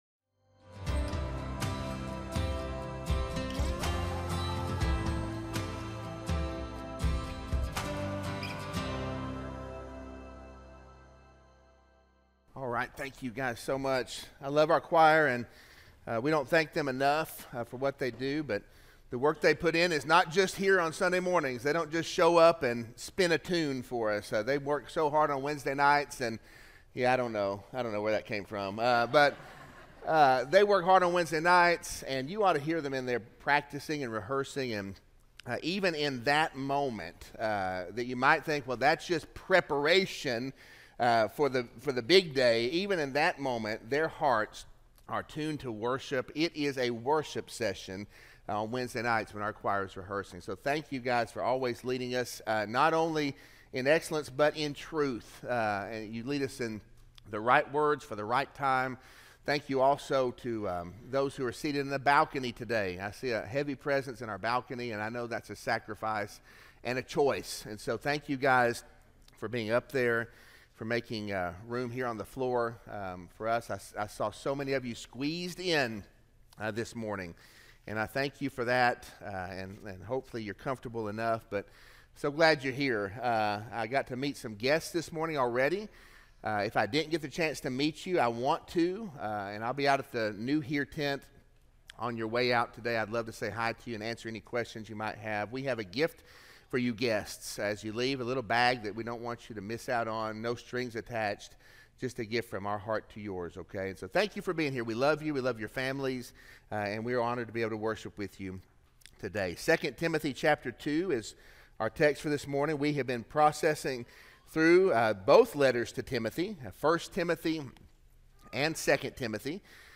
Sermon-3-2-25-audio-from-video.mp3